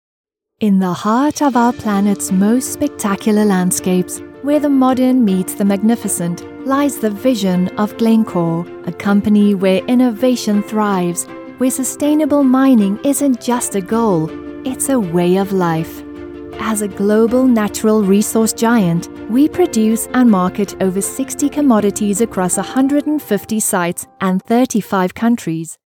Erzählung
Meine Stimme kann zugänglich und freundlich, bestimmend, warm und glaubwürdig oder auch schrullig und lebhaft sein.
Behringer C1 Kondensatormikrofon
Schallisolierter Raum
HochMezzosopran